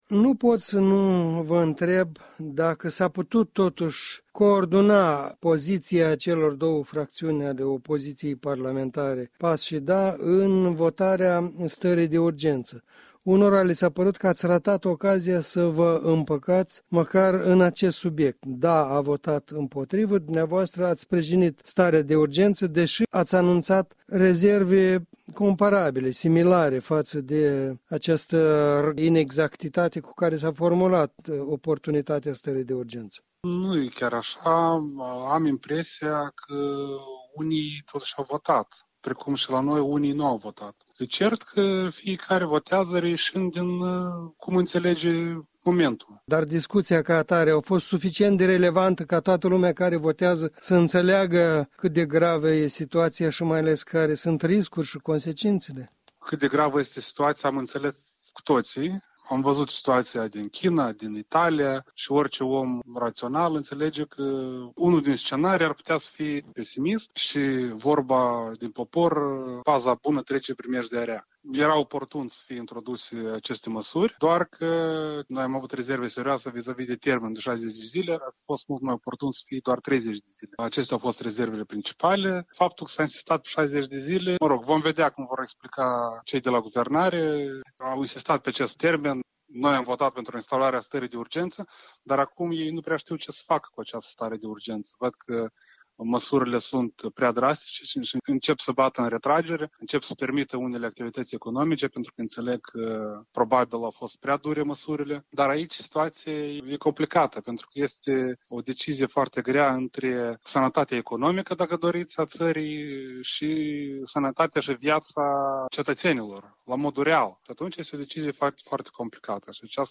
Unii comentatori sunt de părere că, la votul pentru instaurarea stării de urgență, partidele din opoziție nu și-au coordonat viziunea și au ratat o nouă ocazie de a acționa în comun. Radio Europa Liberă a stat de vorbă cu Mihai Popșoi, vicepreședinte al Parlamentului, deputat PAS.
Interviu cu Mihai Popșoi